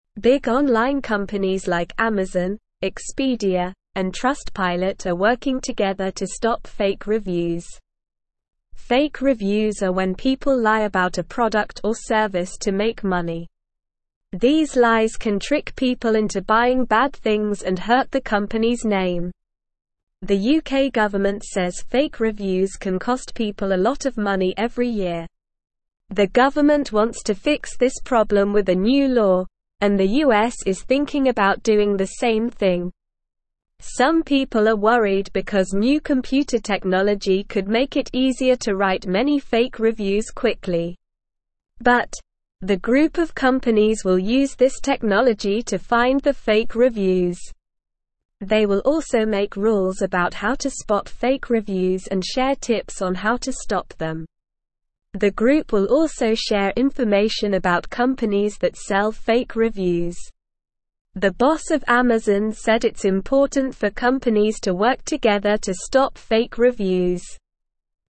Slow
English-Newsroom-Beginner-SLOW-Reading-Big-Companies-Join-Forces-to-Stop-Fake-Reviews.mp3